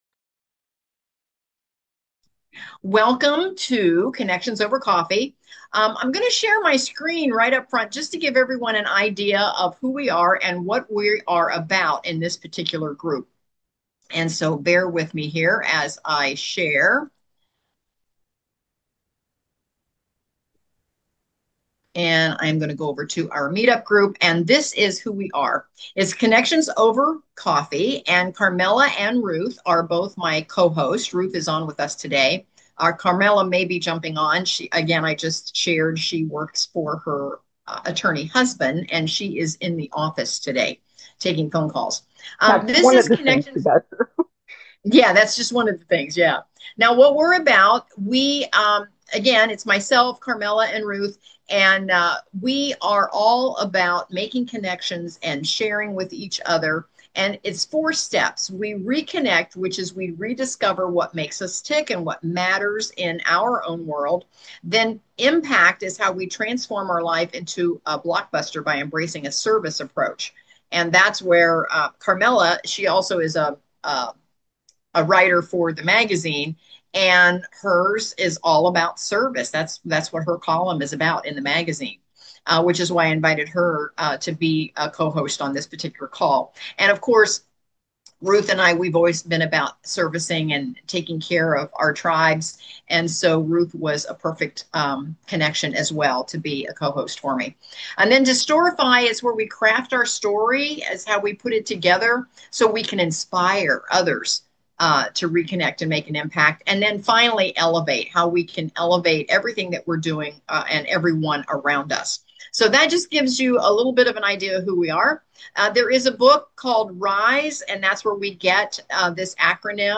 a virtual party on Zoom that's all about connections, networking, laughter, and some good vibes